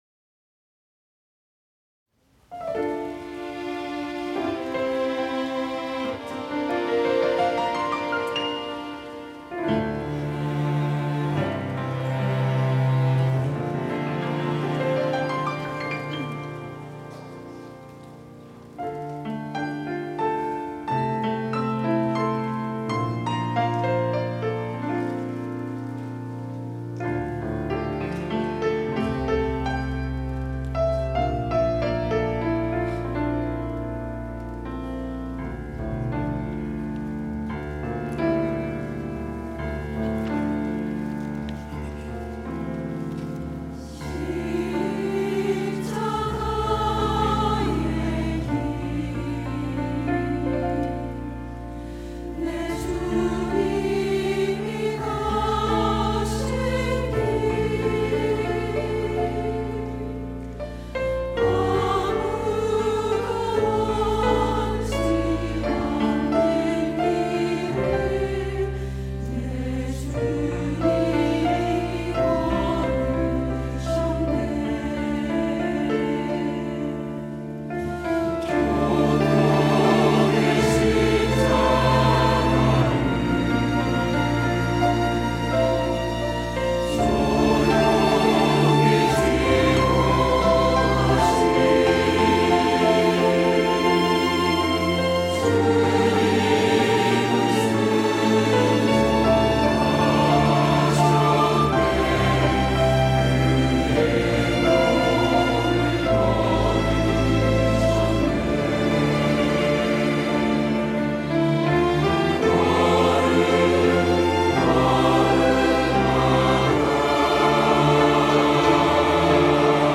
호산나(주일3부) - 십자가
찬양대